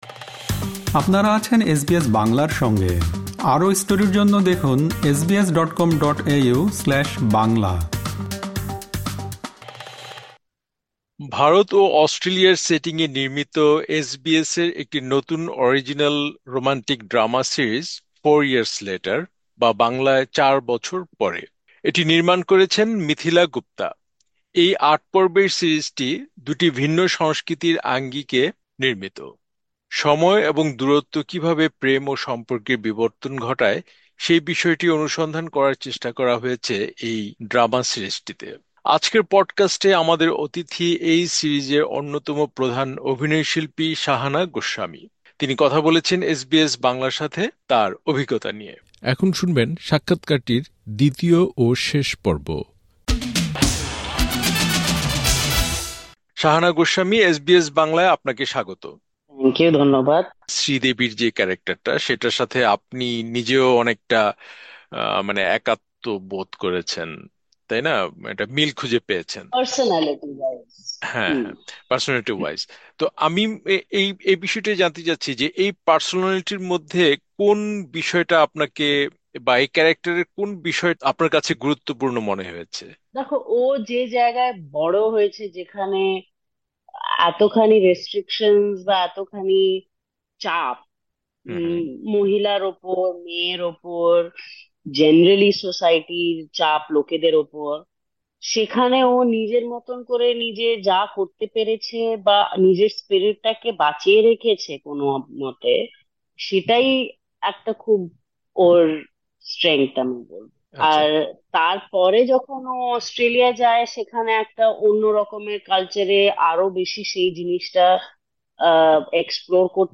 ড্রামা সিরিজ 'ফোর ইয়ার্স লেটার' - এর মূল অভিনেত্রী শাহানা গোস্বামীর সাথে আলাপচারিতা - দ্বিতীয় পর্ব
আজকের পডকাস্টে, আমাদের অতিথি এই সিরিজের অন্যতম প্রধান অভিনয় শিল্পী শাহানা গোস্বামী।